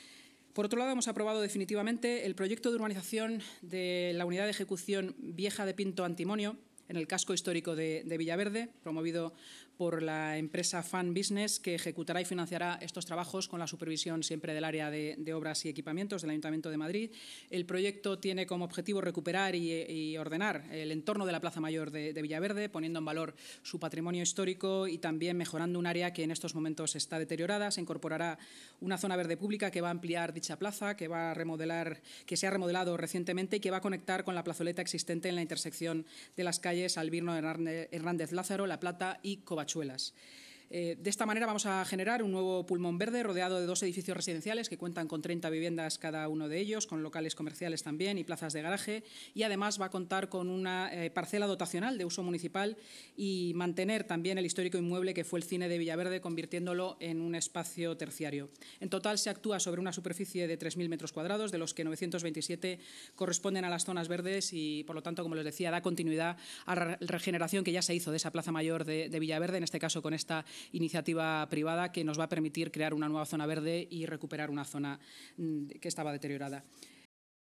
La Junta de Gobierno municipal ha aprobado hoy definitivamente el proyecto de urbanización de la unidad de ejecución Vieja de Pinto-Antimonio, en el casco histórico de Villaverde, promovido por Fun Business S. L., que ejecutará y financiará los trabajos con la supervisión del Área de Obras y Equipamientos, según ha informado en rueda de prensa la vicealcaldesa y portavoz municipal, Inma Sanz.